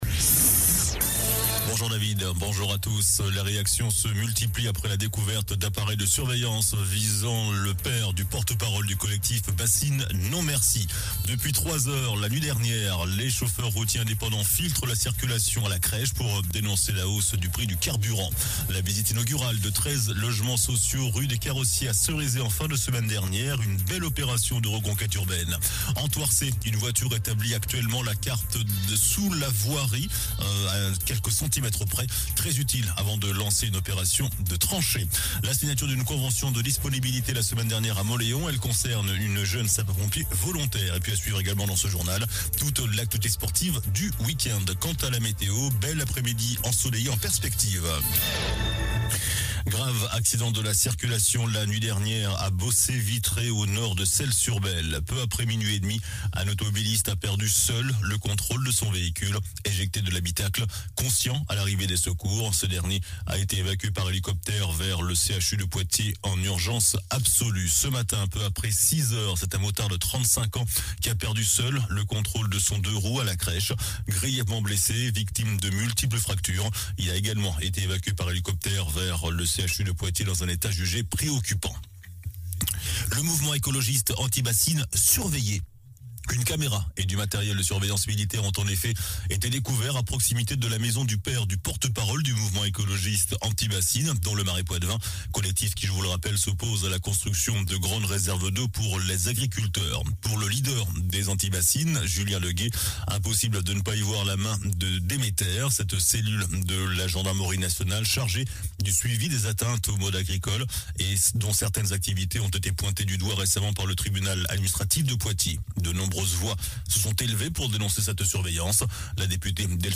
JOURNAL DU LUNDI 21 MARS ( MIDI )